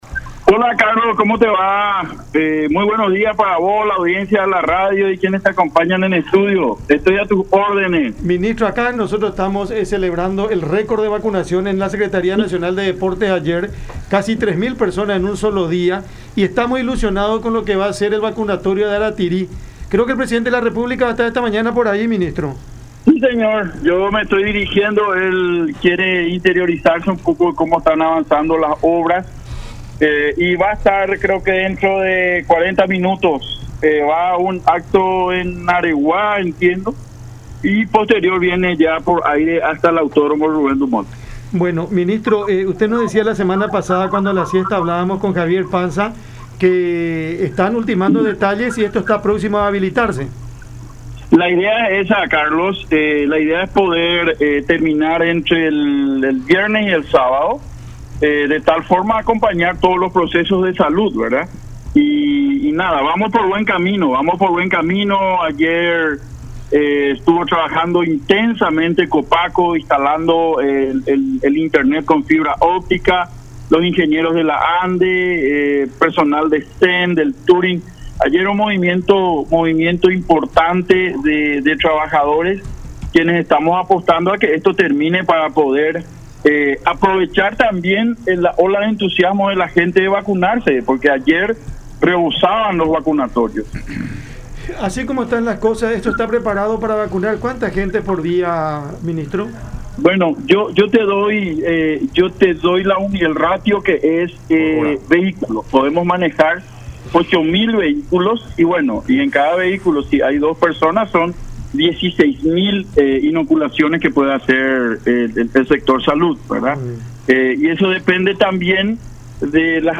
Estamos trabajando intensamente porque también queremos aprovechar la ola de entusiasmo de la gente por querer vacunarse”, destacó Roa en contacto con Cada Mañana por La Unión.